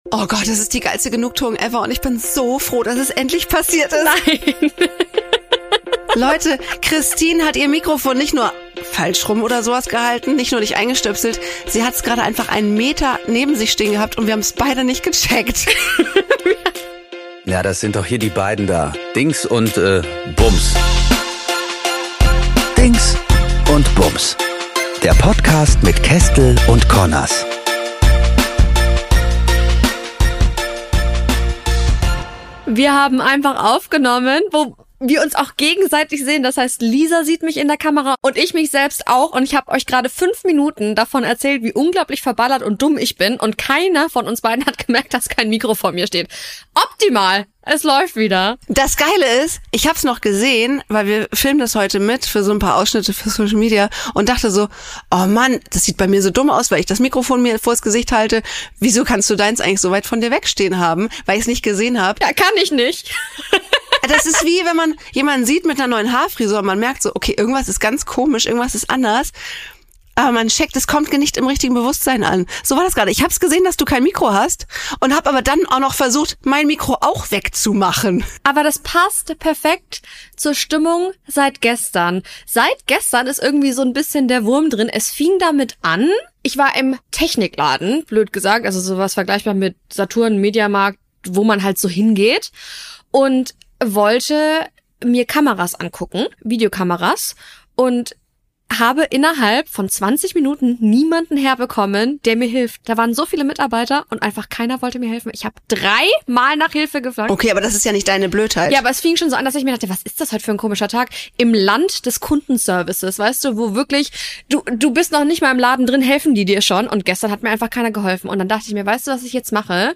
Untermalt wird das Ganze von fragwürdigem KI-Schlager - von der Warteschlange bis zum fesselnden Dinner in the Dark.